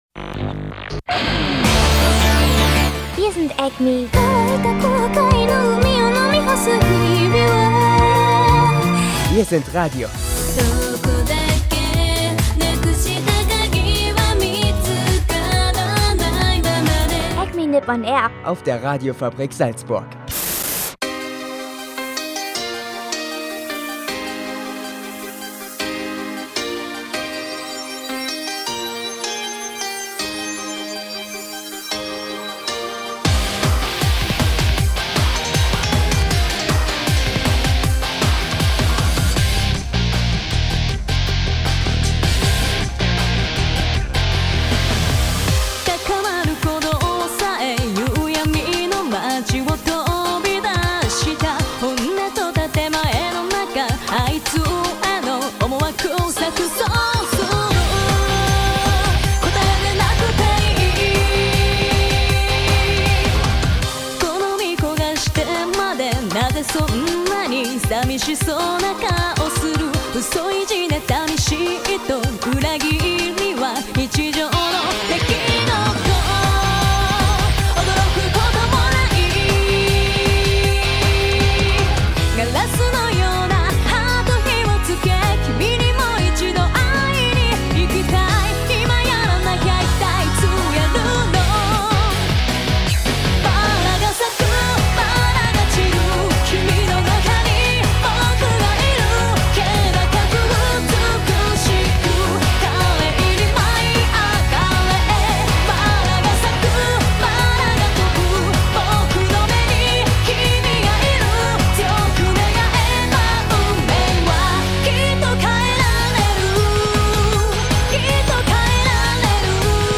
Es ist superheiß, im Studio und auch in der Sendung musikalisch. Außerdem gibt es Anime-News zu Detektiv Conan, Pokemon, Nana Inoue und Japan-News zur Tochter des Tennou, Vergewaltigungsfällen in Japan und dem derzeitigen Gesundheitszustand des X-Japan-Drummers nach seiner Nacken-OP.